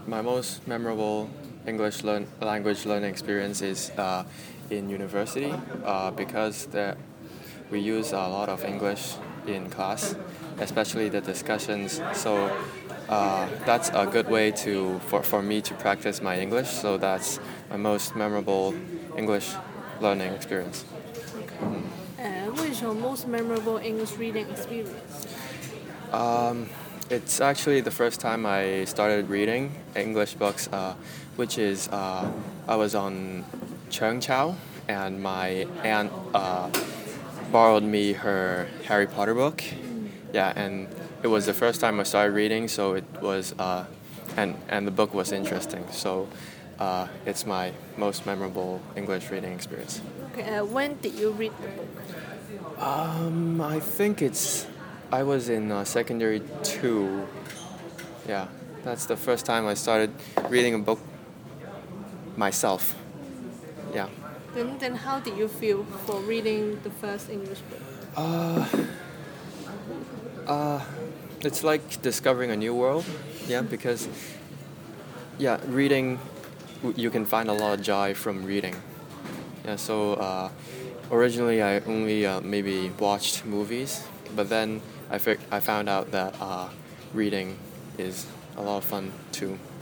Subcategory: Fiction, Reading, Speech